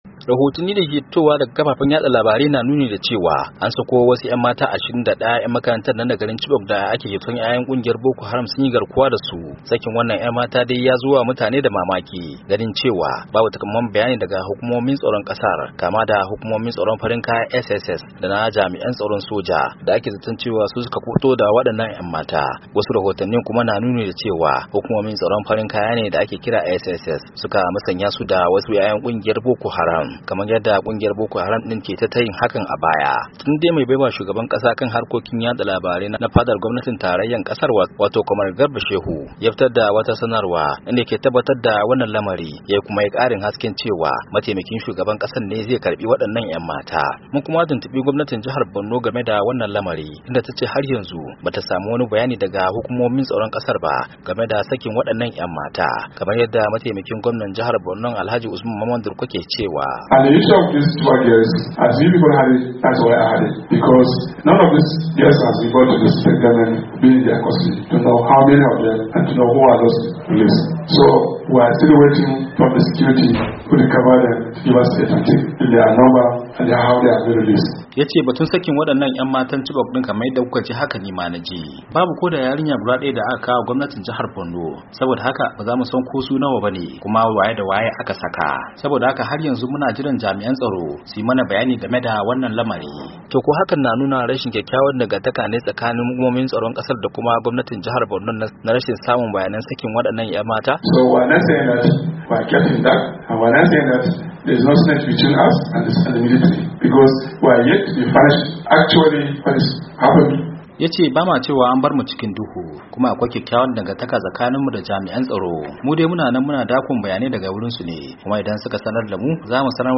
WASHINGTON DC —